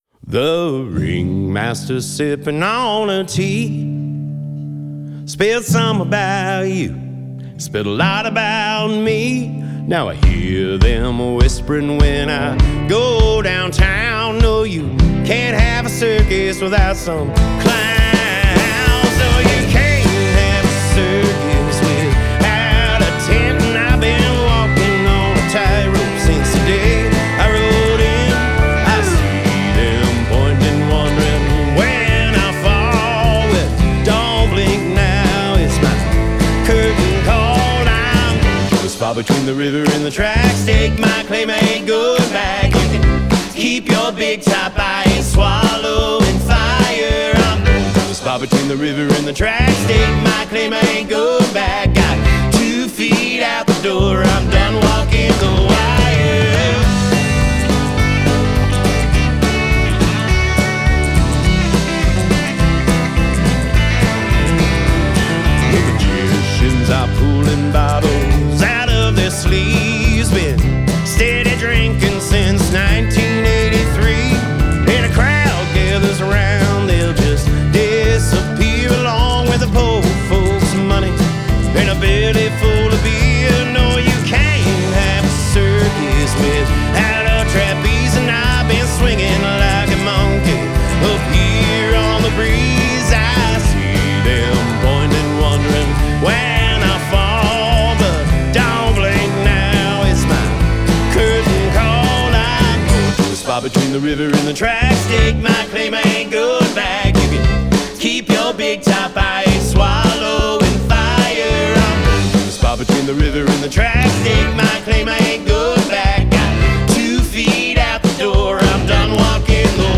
” is a defiant anthem of self-liberation